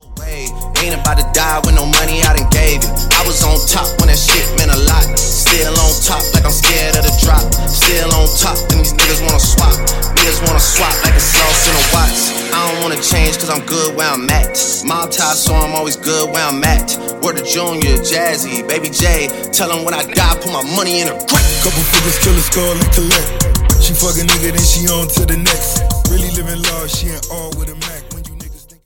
Dj Intro Outro – Get Yours Now & Add To Cart